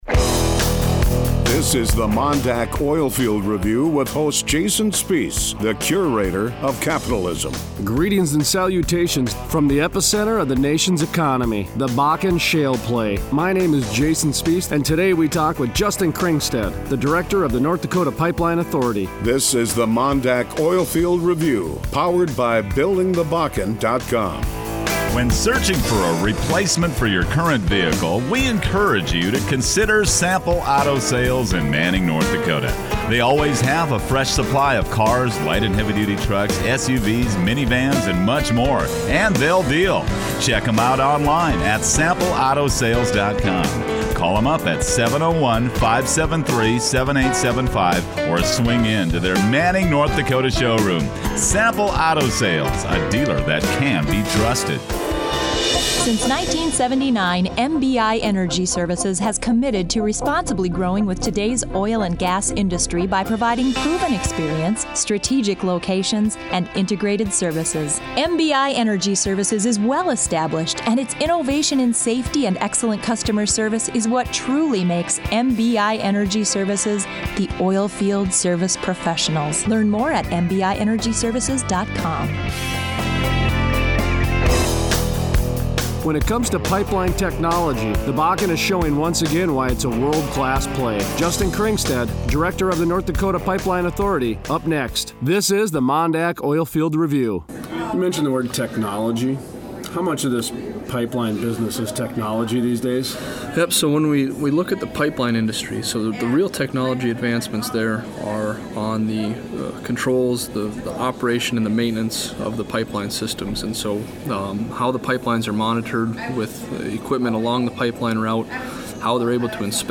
Thursday January 8, 2015 Interview: Justin Kringstad, director, North Dakota Pipeline Authority